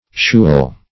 shewel - definition of shewel - synonyms, pronunciation, spelling from Free Dictionary Search Result for " shewel" : The Collaborative International Dictionary of English v.0.48: Shewel \Shew"el\, n. A scarecrow.